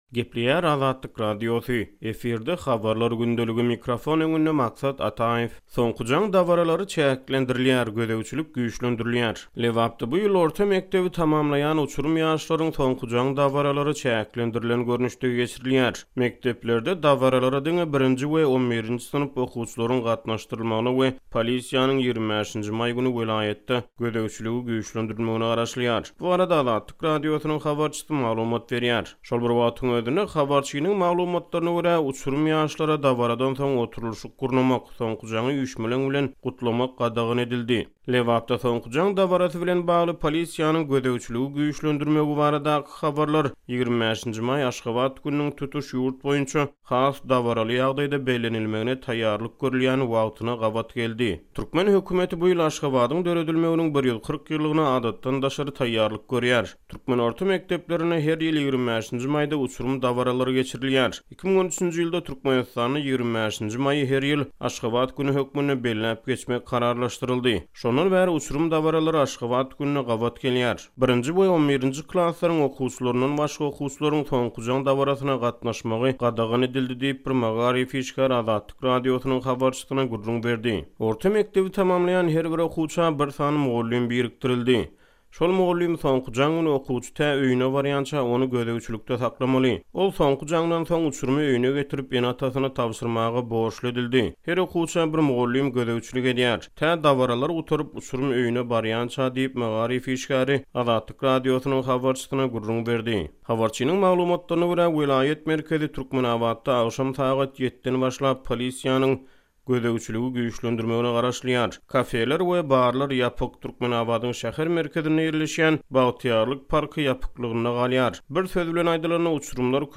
Bu barada Azatlyk Radiosynyň habarçysy maglumat berýär.